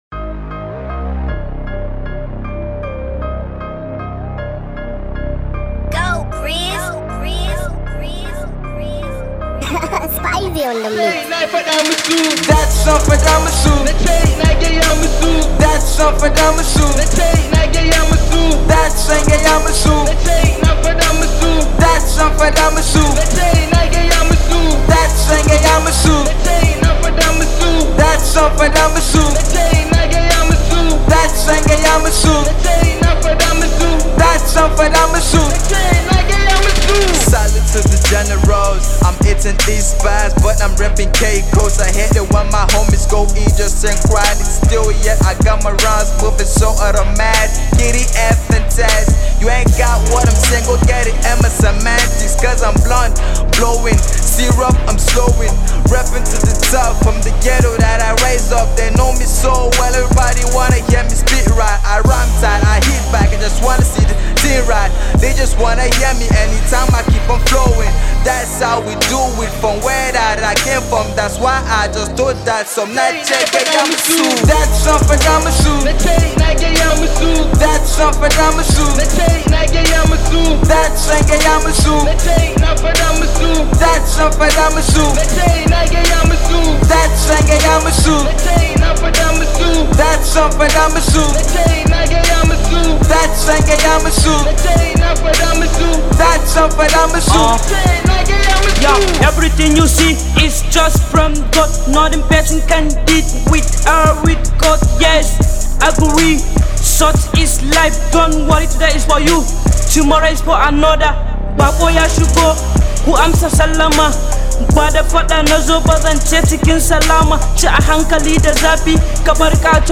Arewa Rapper